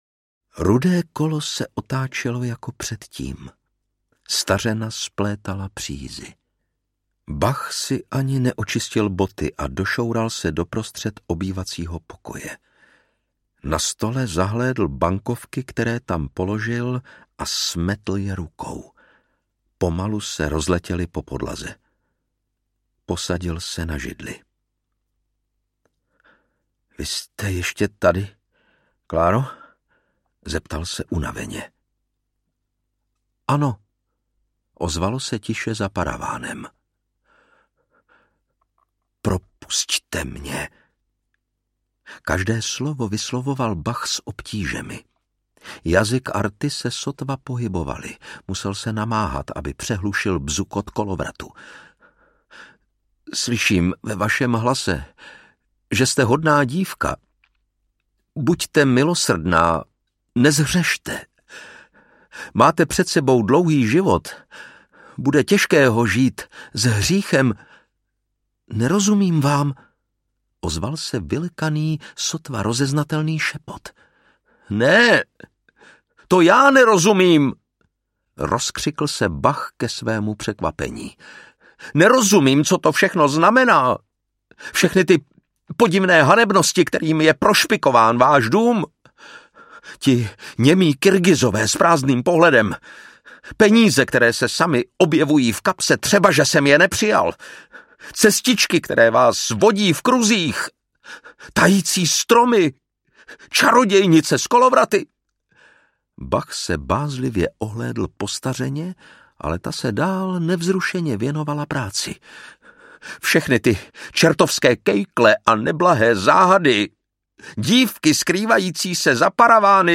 Ukázka z knihy
Čte Lukáš Hlavica.
Vyrobilo studio Soundguru.